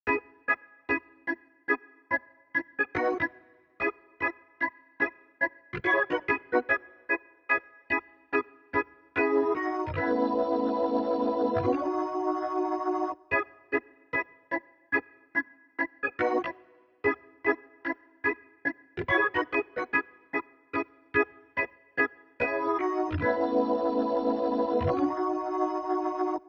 02 organ A.wav